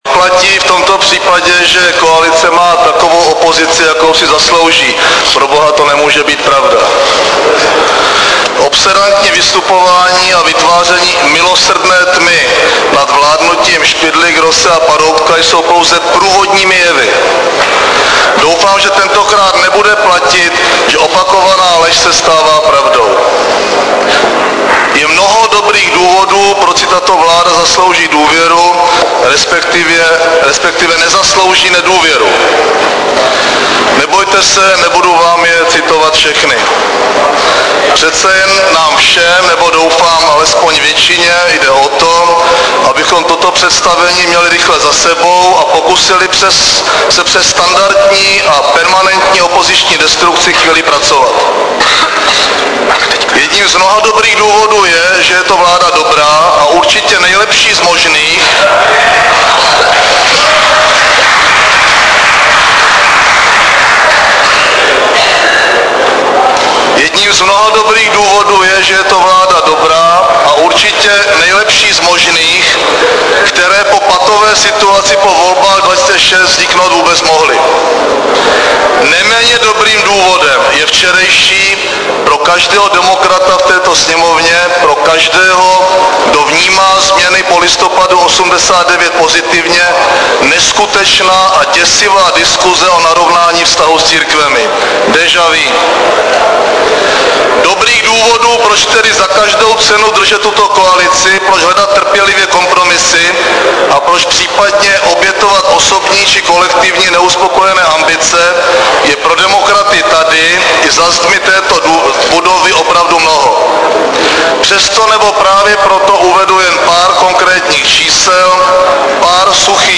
Projev premiéra Mirka Topolánka v PSP ČR před hlasováním o návrhu na vyslovení nedůvěry vládě 30.4.2008